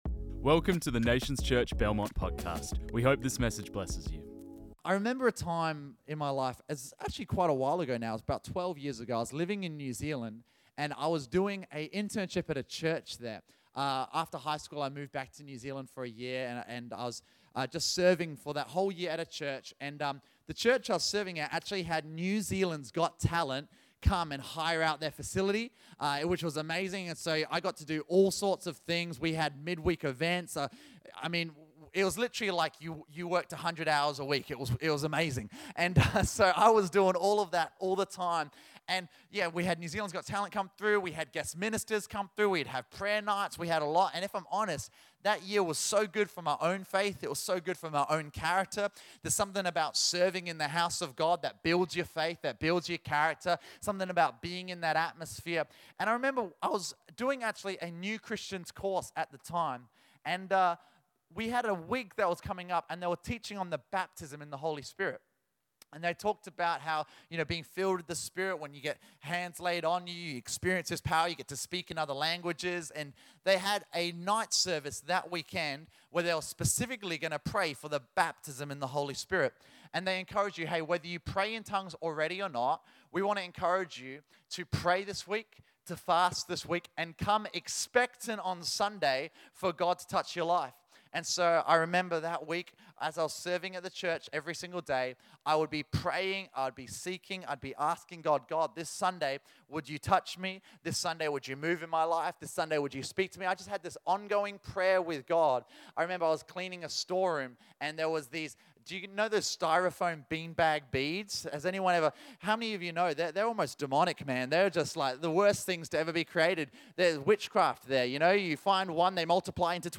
This message was preached on 07 April 2024.